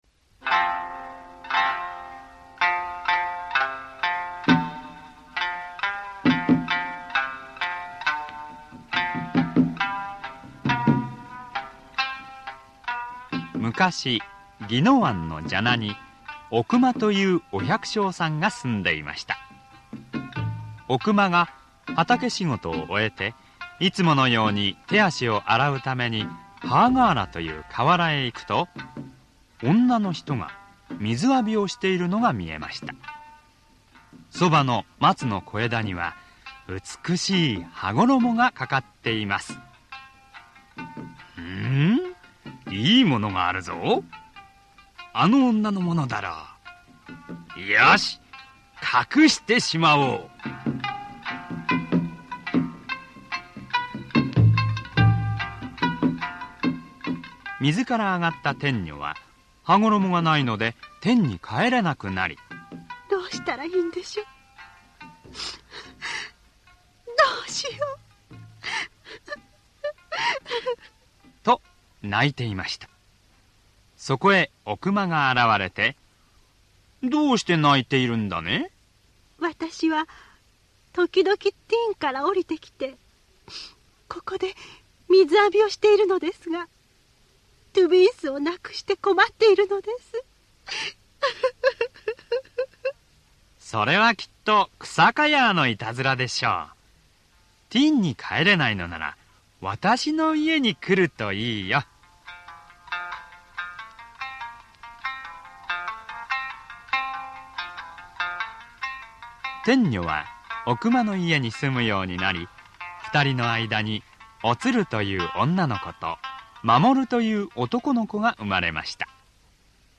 [オーディオブック] 察度王の話